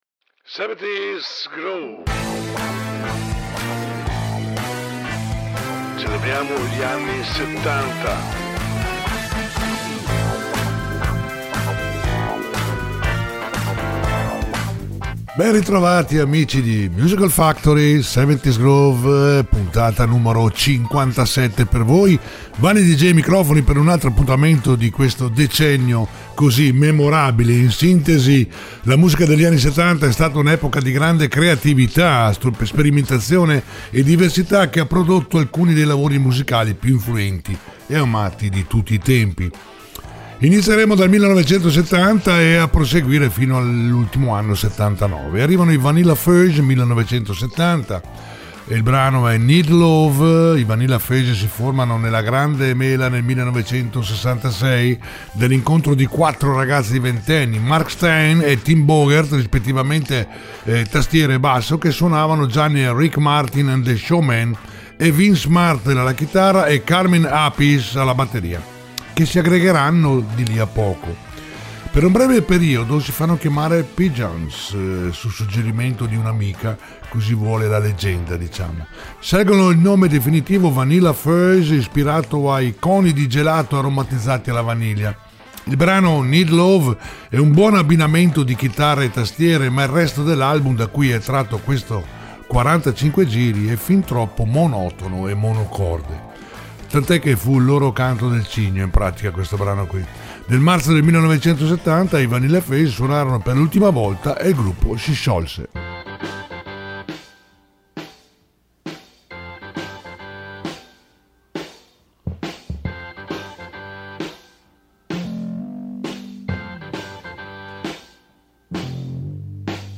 70's Groove